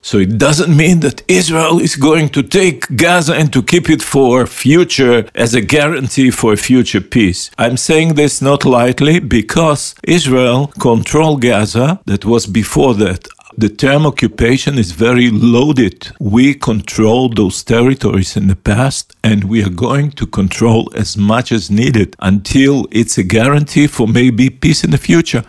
O svemu smo u Intervjuu tjedna Media servisa razgovarali s izraelskim veleposlanikom u Hrvatskoj Garyjem Korenom koji je poručio: "Mi nismo ludi ljudi, ne želimo još desetljeća nasilja; lideri trebaju glasno reći Hamasu da je dosta!"